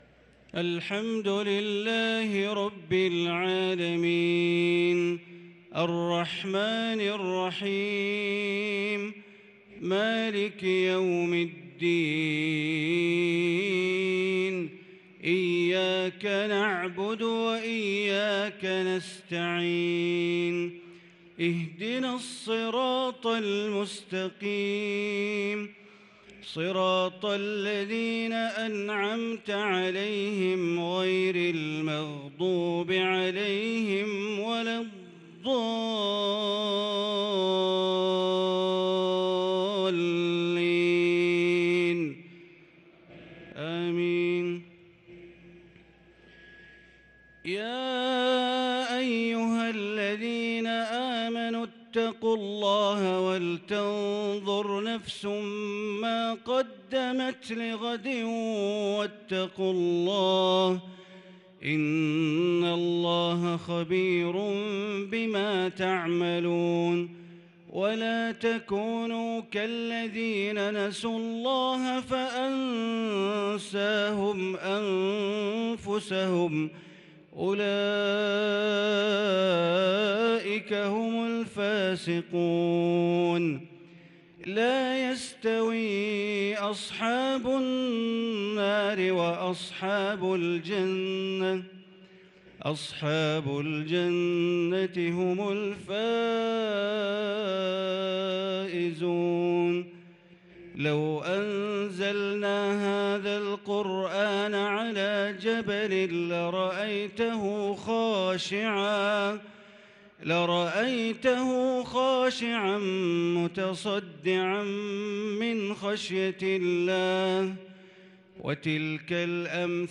عشاء السبت 8-9-1443هـ خواتيم سورة الحشر | Isha prayer from Surah Al-Hashr 9-4-2022 > 1443 🕋 > الفروض - تلاوات الحرمين